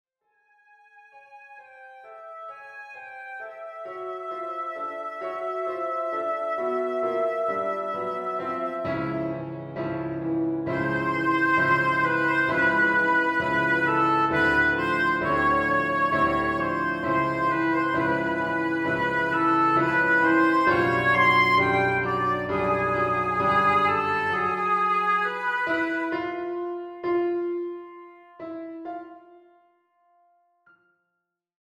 Excerpt from the Second Movement